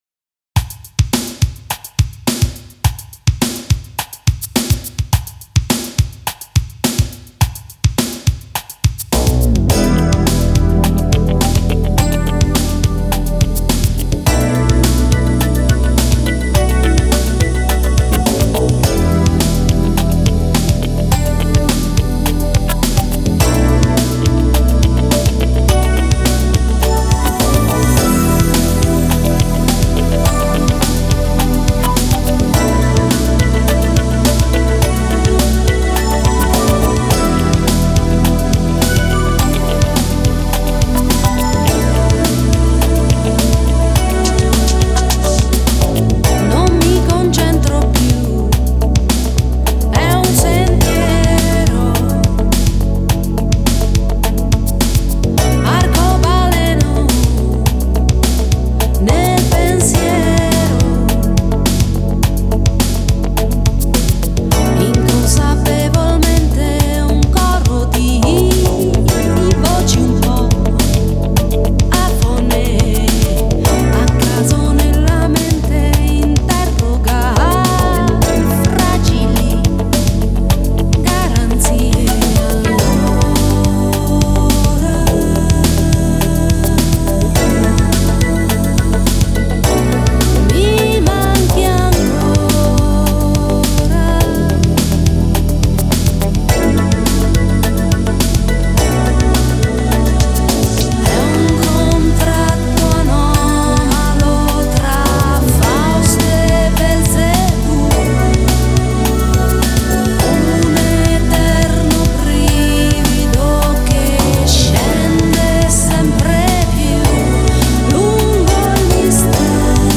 Genre: Pop, Pop-Rock, New Wave, Electronic